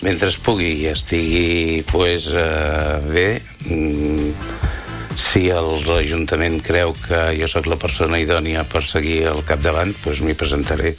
Ho ha dit a l’entrevista de l’FM i +: